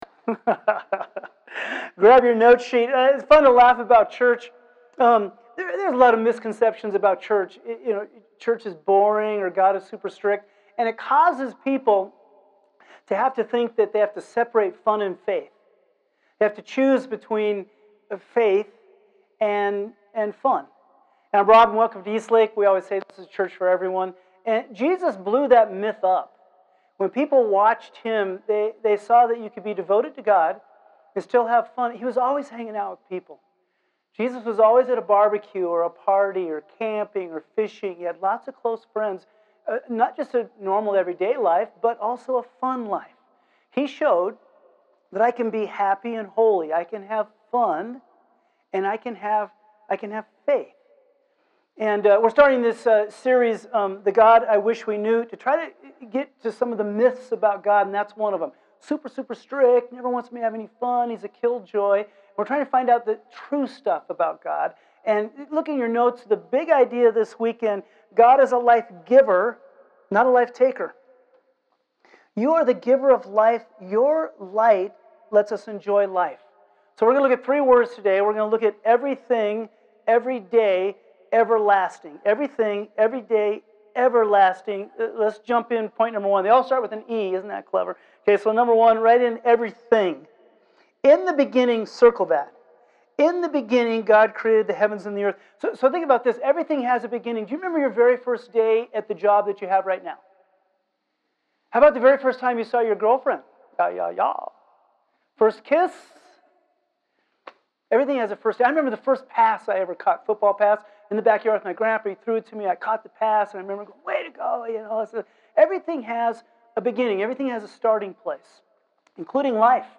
Sermon Audio — Page 2